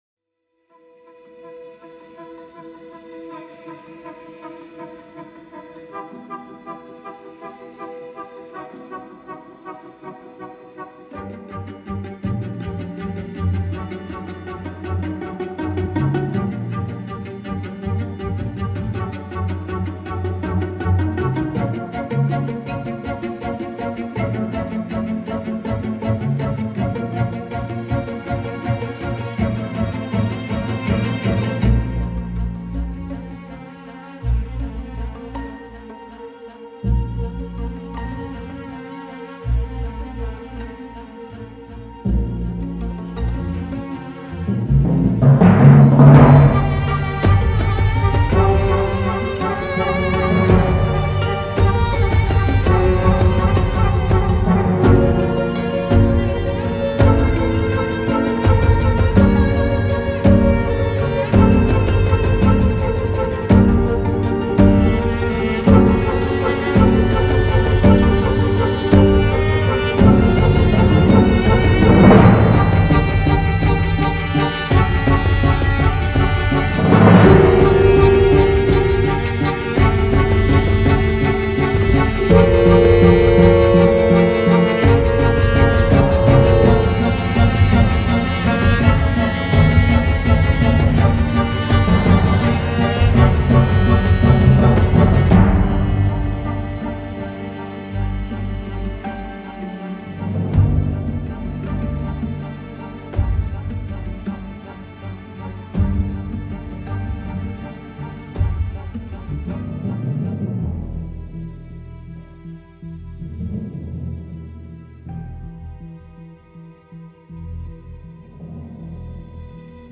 Triste mais beau.